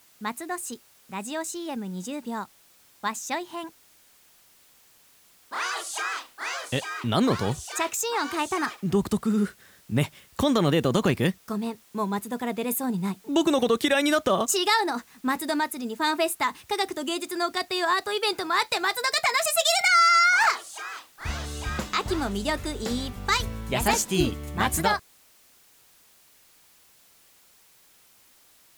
• 放送媒体／ FMラジオ局 BAYFM78（ベイエフエム）78.0MHz
• 放送回数／上記放送時間内で、1日1回または2回、20秒間放送（全23回）
• 若いカップルがコミカルなテンポで松戸の秋の3大イベント「松戸まつり」「松戸ファンフェスタ」「科学と芸術の丘」を熱く楽しくPRします